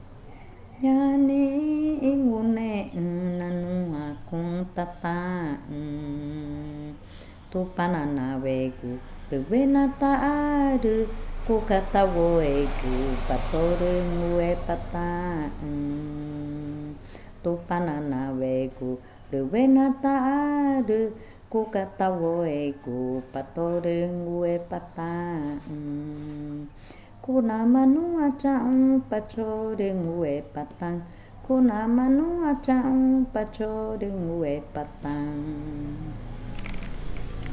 Canción infantil 16. Hasta pronto mi escuelita
Cushillococha